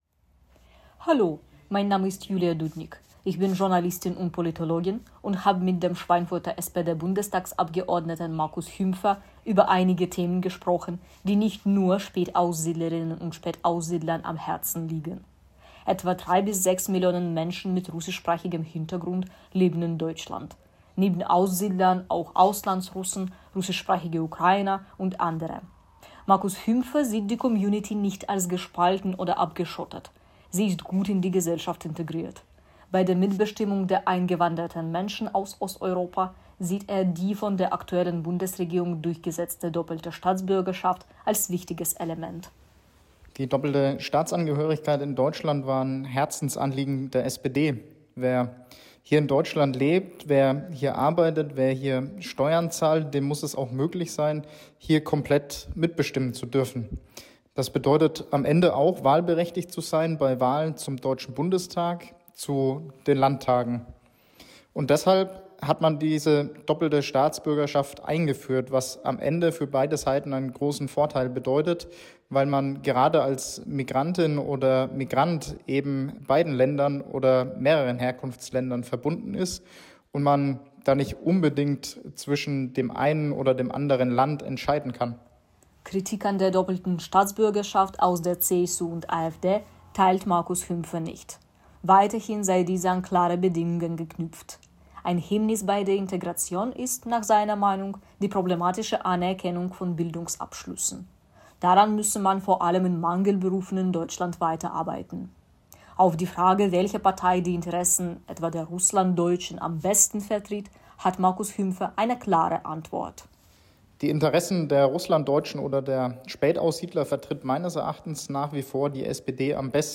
Einen Podcast mit wichtigen Ausschnitten dieses Gesprächs finden Sie hier.